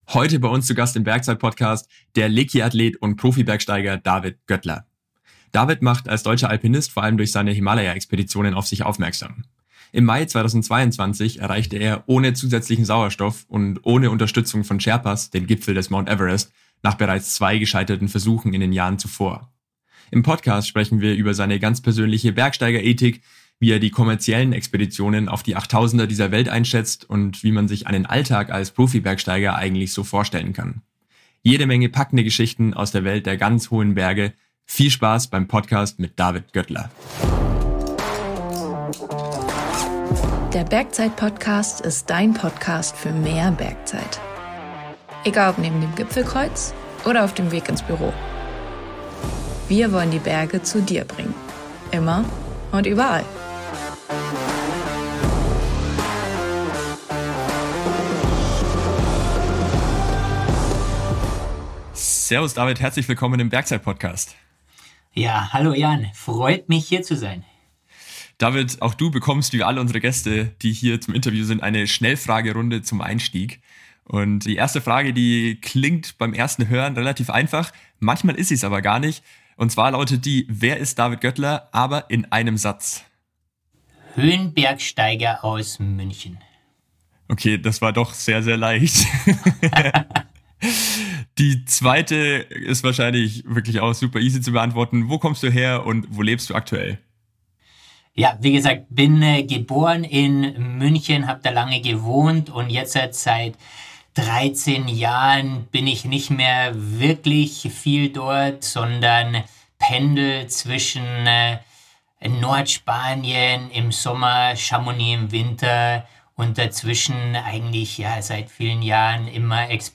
#73 Interview mit Kilian Fischhuber – Bergzeit Podcast – Lyssna här